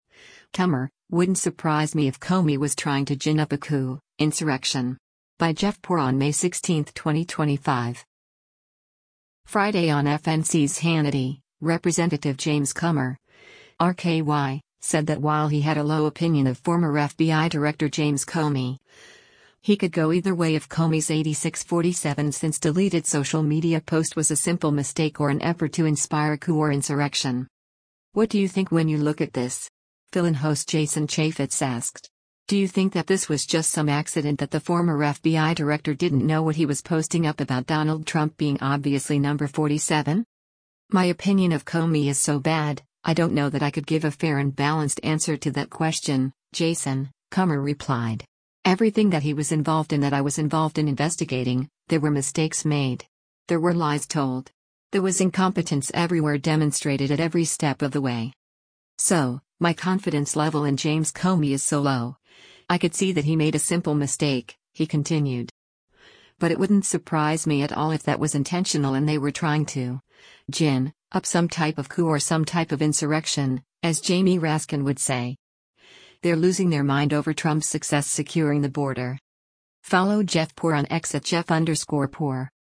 “What do you think when you look at this?” fill-in host Jason Chaffetz asked.